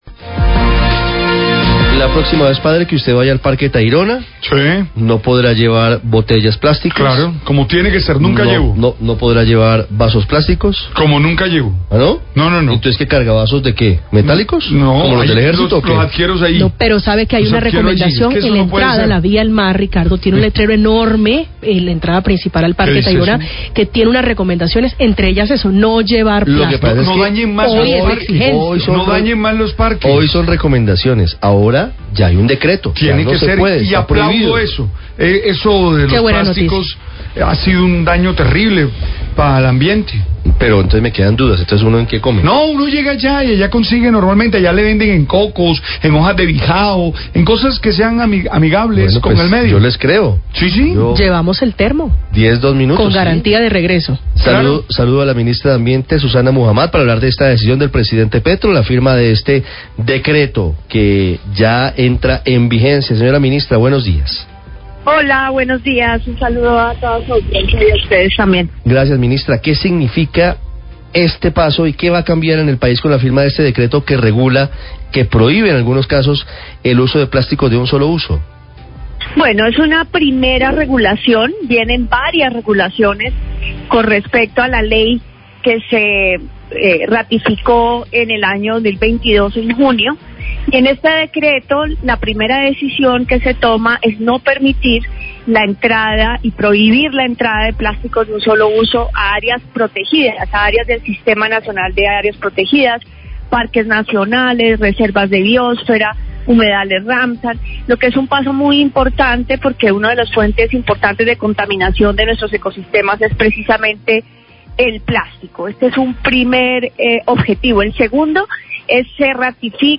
Radio
La Ministra de Ambiente y Desarrollo Sostenible, Susana Muhamad, habla del decreto presidencial que prohibe el ingreso de plásticos de un sólo uso a las áreas protegidas, Parques Naturales, Áreas Ramsar, entre otras zonas naturales.